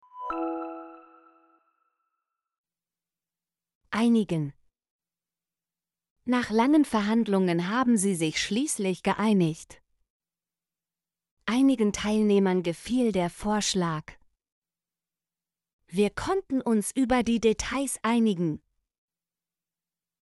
einigen - Example Sentences & Pronunciation, German Frequency List